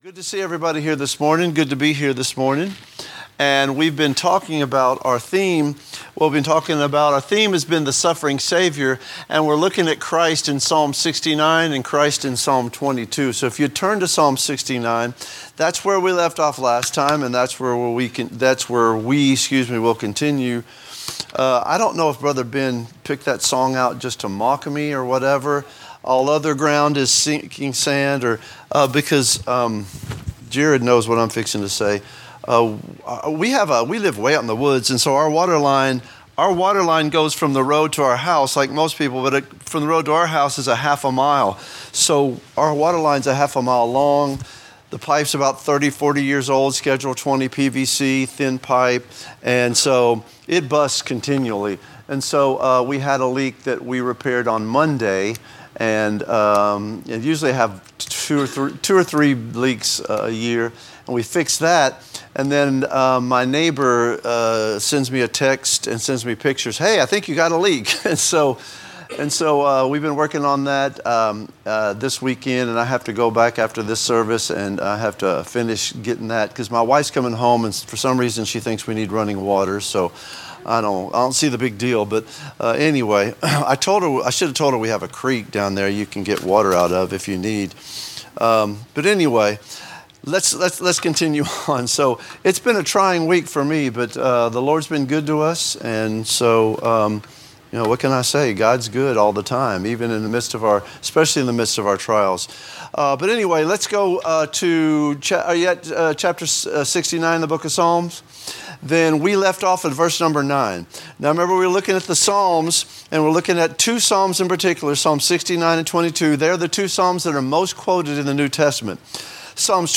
A message from the series "The Suffering Saviour."